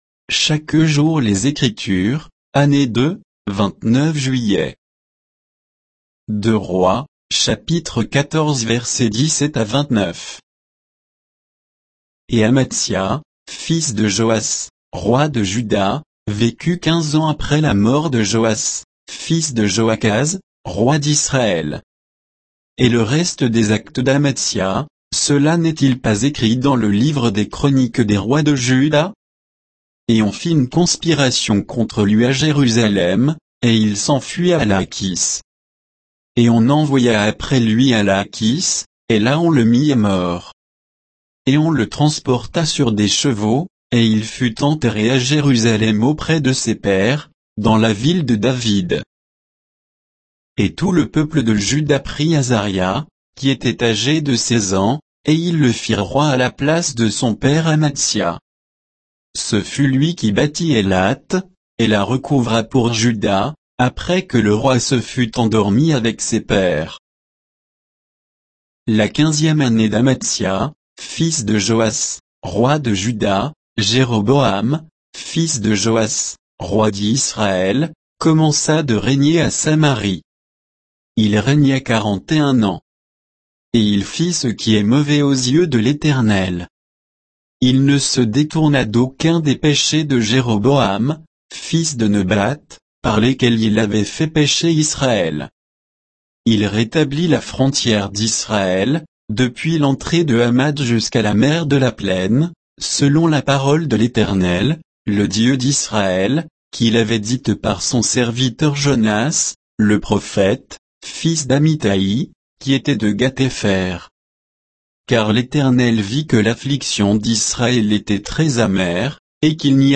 Méditation quoditienne de Chaque jour les Écritures sur 2 Rois 14, 17 à 29